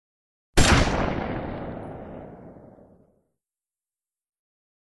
Звук пострілу снайперської гвинтівки в одиночному режимі